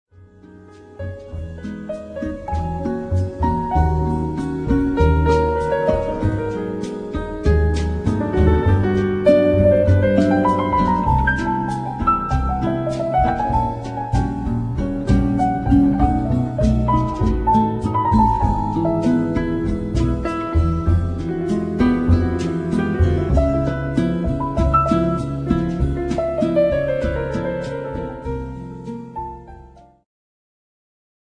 ジャンル Jazz
Progressive
癒し系
二胡